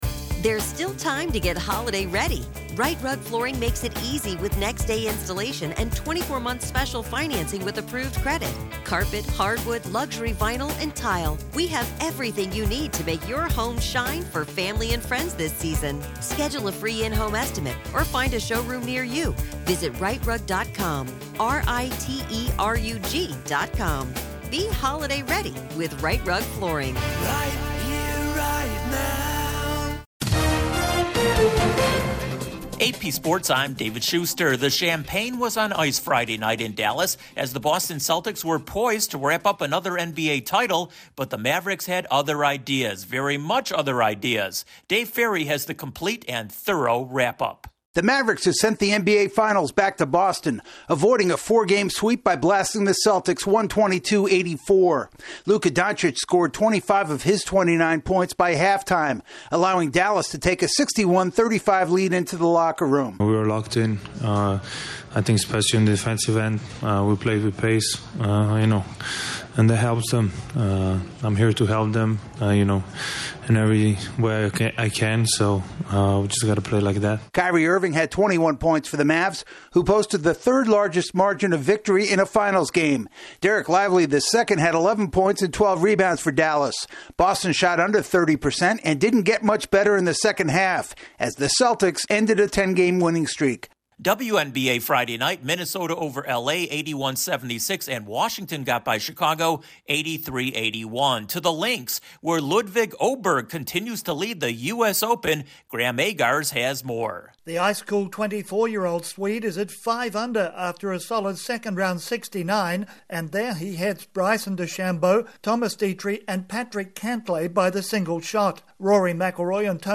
The Mavericks refuse to be swept, a tight leaderboard at golf's U.S. Open and a Major League Baseball umpire gets the thumb. Correspondent